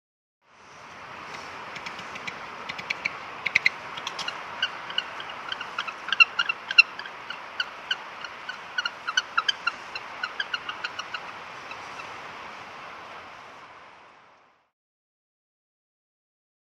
Penguin Chirps. A Single Penguin Chirps With Ocean Ambience In The Background. Medium Perspective.